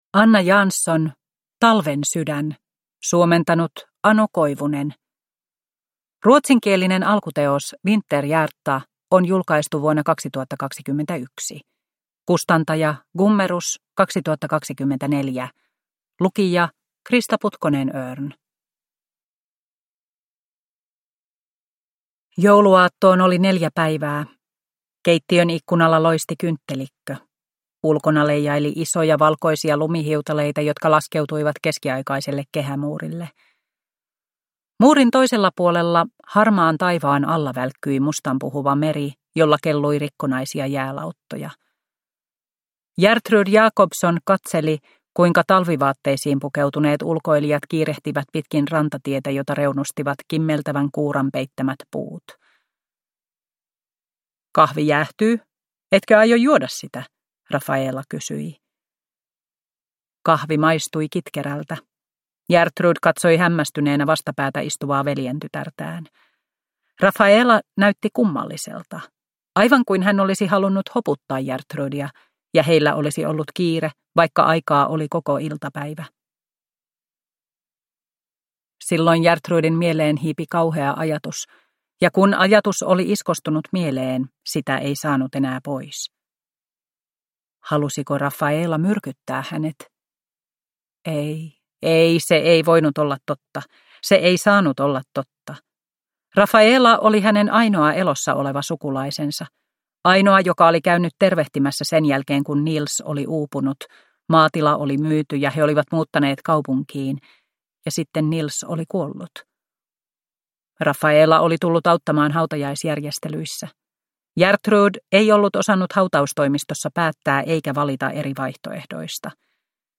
Talvensydän – Ljudbok